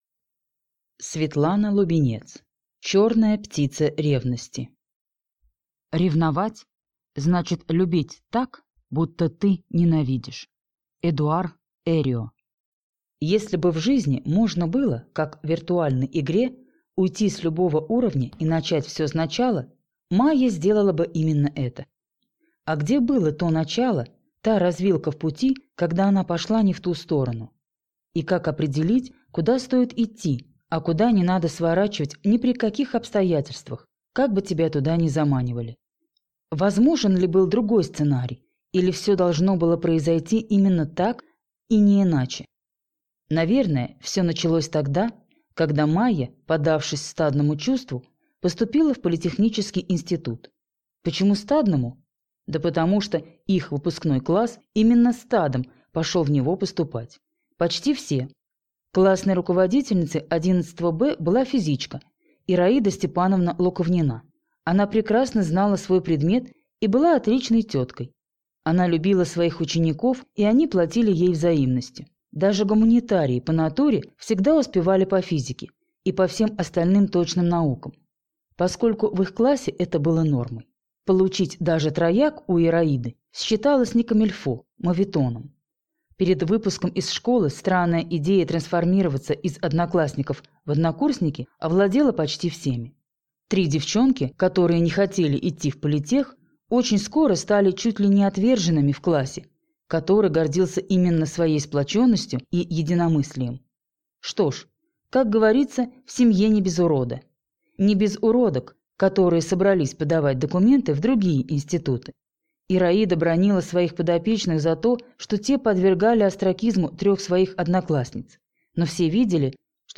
Аудиокнига Черная птица ревности | Библиотека аудиокниг